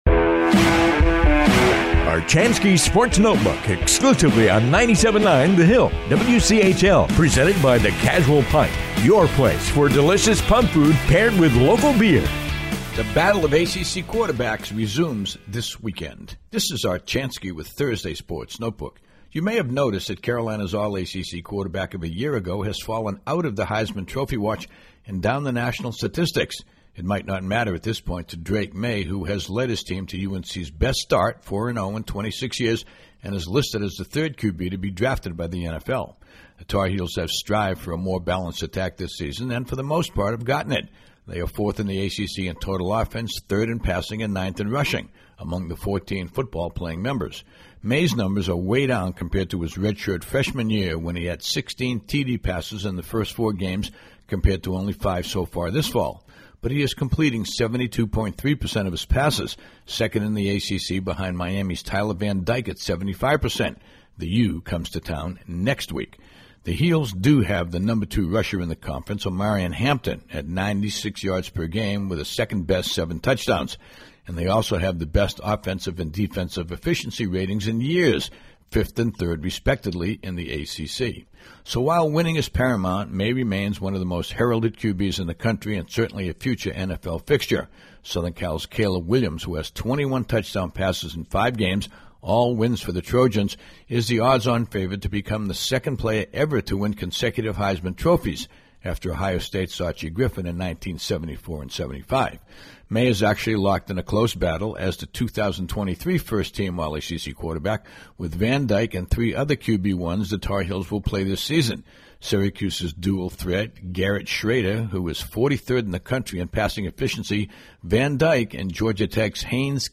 “Sports Notebook” commentary airs daily on the 97.9 The Hill WCHL